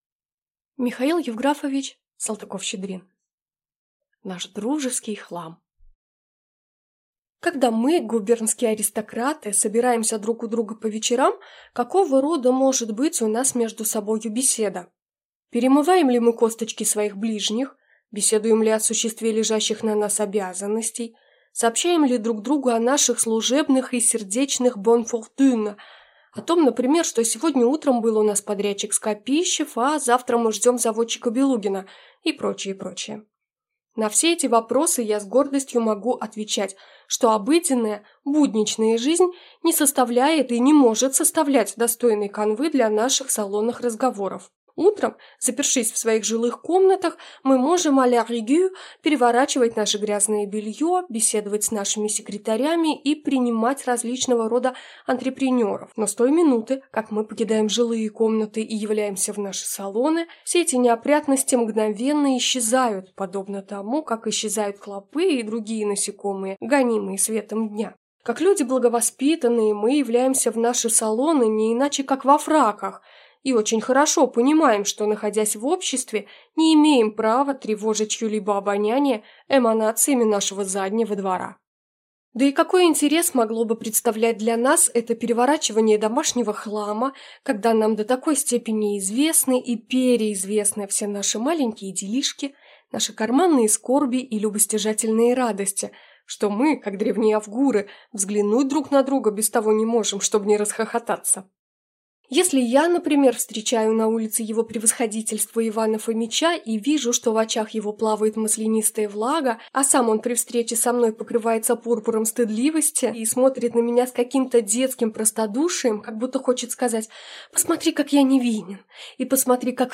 Аудиокнига Наш дружеский хлам | Библиотека аудиокниг